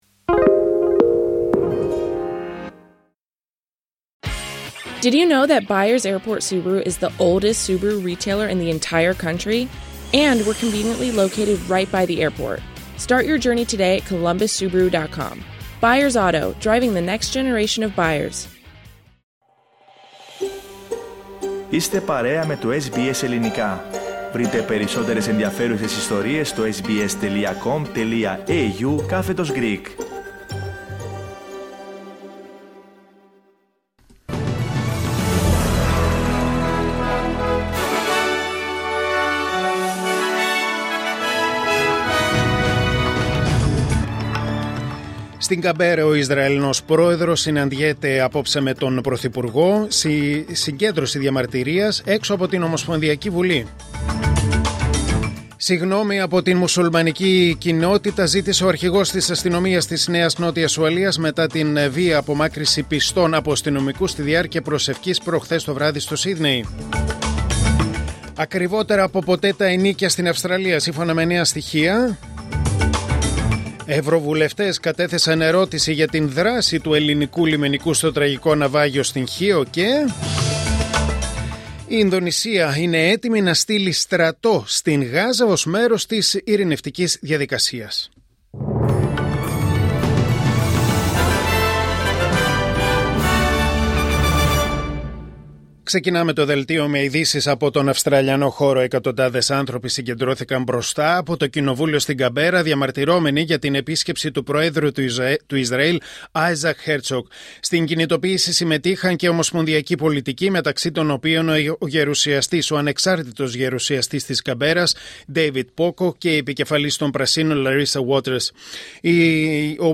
Δελτίο Ειδήσεων Τετάρτη 11 Φεβρουαρίου 2026